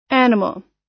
Транскрипция и произношение слова "animal" в британском и американском вариантах.
animal__us_1.mp3